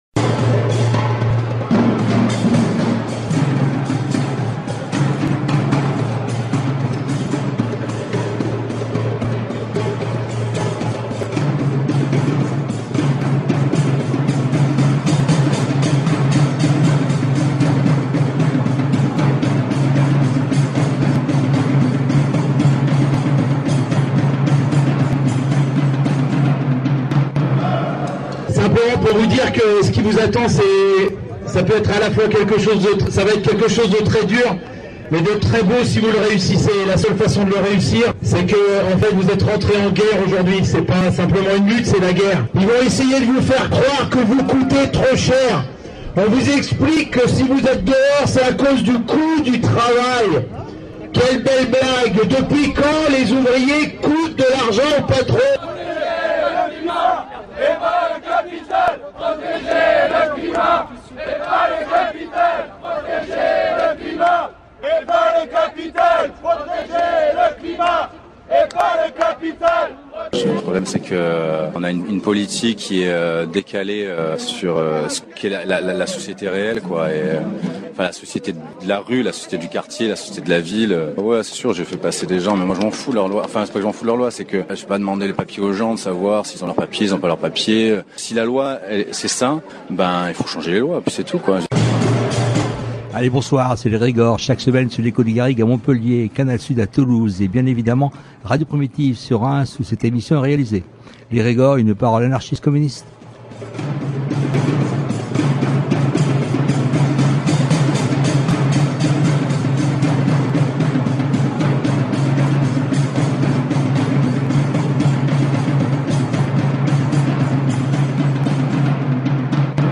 Le 3 juin se tenait donc à la Bourse du travail une assemblée générale contre ces OQTF. Nous vous diffusons donc aujourd’hui le montage d’une partie des prises de parole qui ont eu lieu lors de ce meeting.